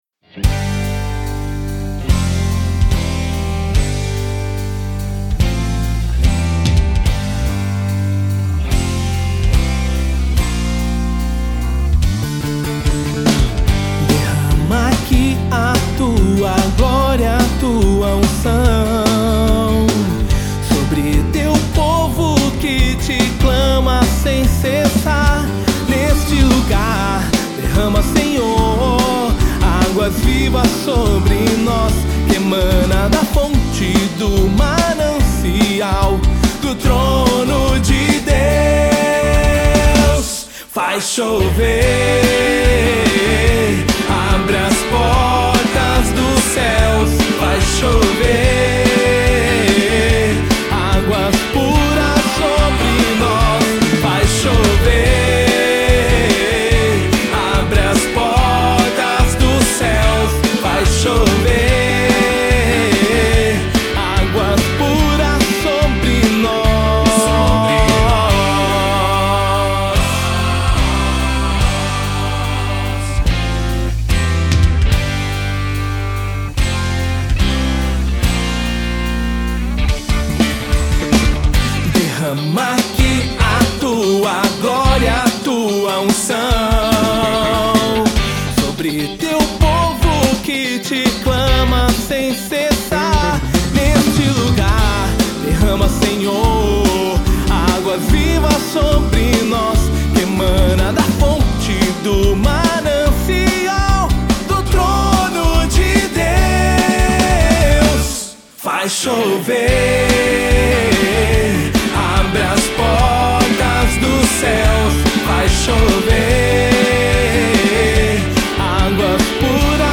EstiloCatólica